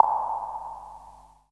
PINGPONG  -L.wav